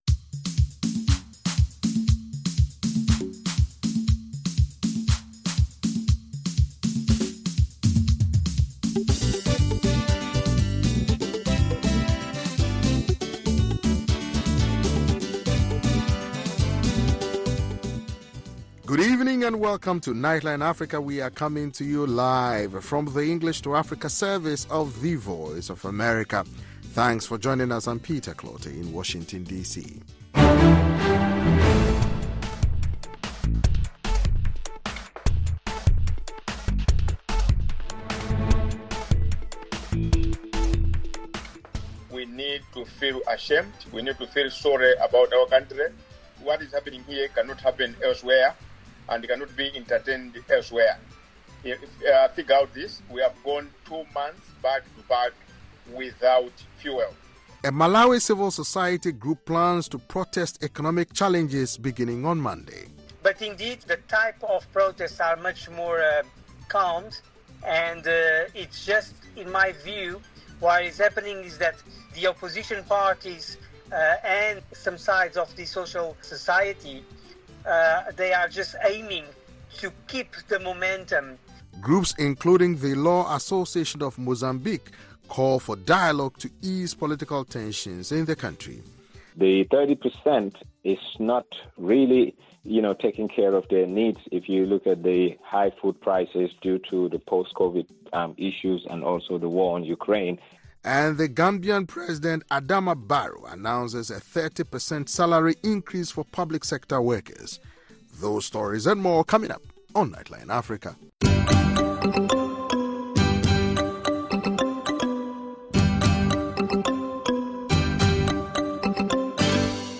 Nightline Africa is a 60-minute news magazine program highlighting the latest issues and developments on the continent. Correspondents from Washington and across Africa offer in-depth interviews, analysis and features on African arts and culture, sports, and music